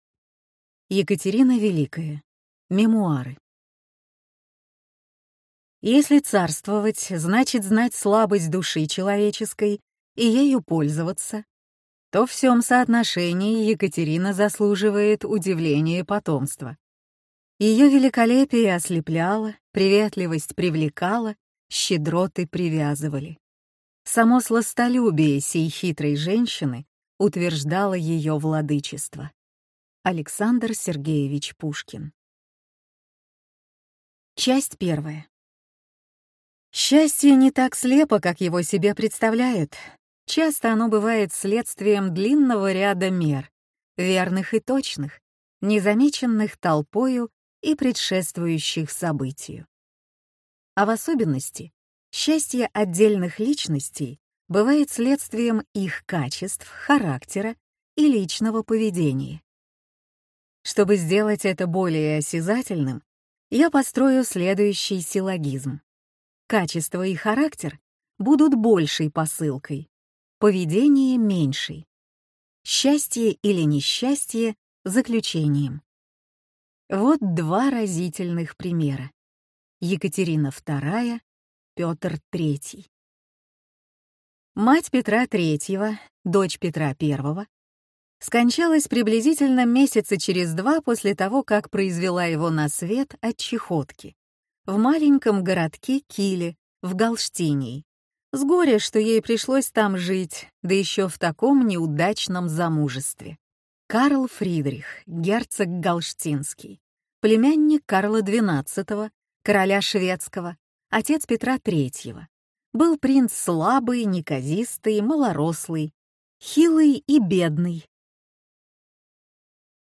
Аудиокнига Мемуары | Библиотека аудиокниг